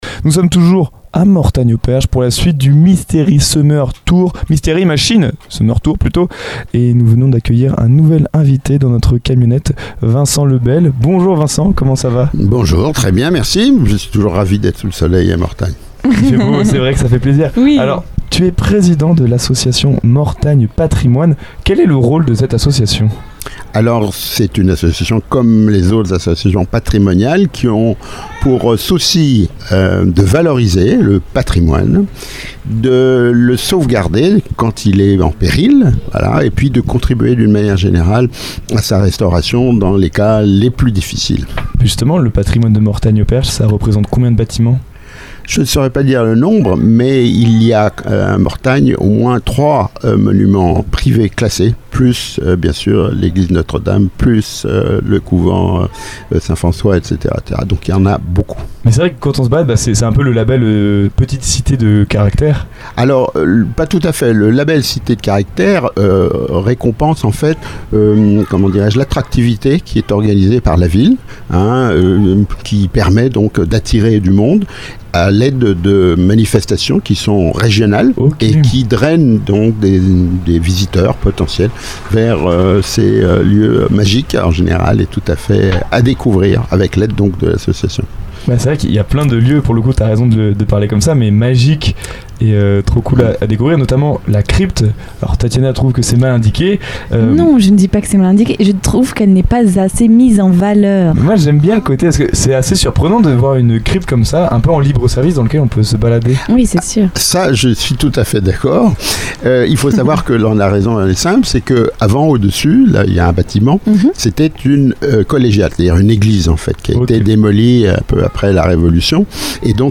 Une interview passionnée et instructive qui met en lumière le rôle essentiel du tissu associatif dans la sauvegarde et la mise en valeur du patrimoine mortagnais. Mystery Machine Mortagne-au-Perche